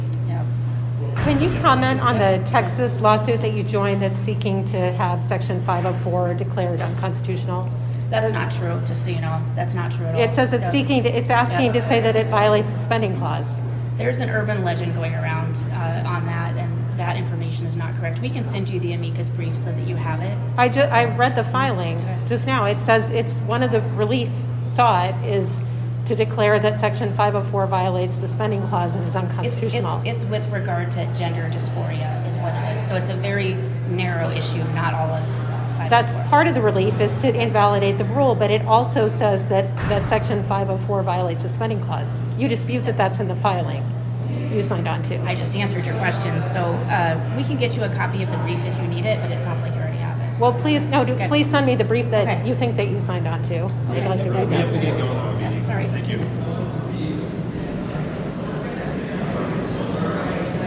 After her appearance, the attorney general took some questions from reporters in the hallway.